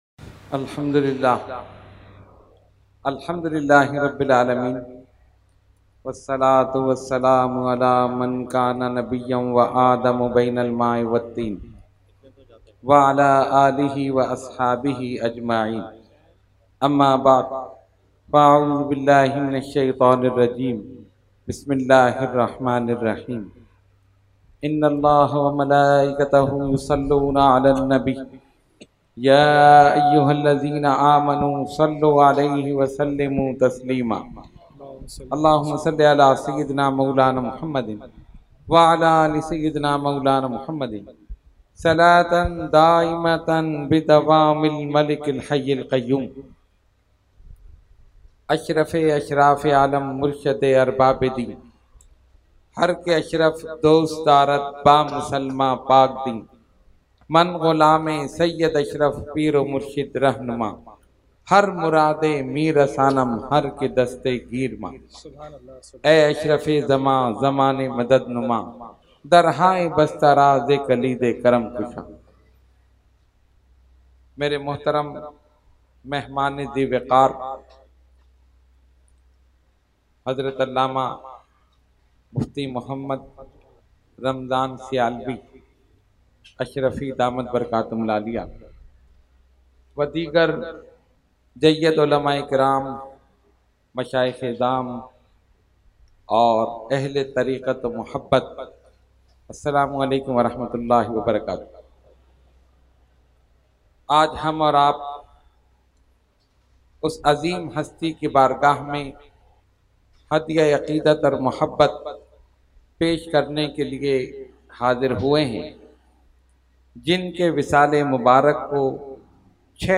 Category : Speech | Language : UrduEvent : Urs Makhdoome Samnani 2020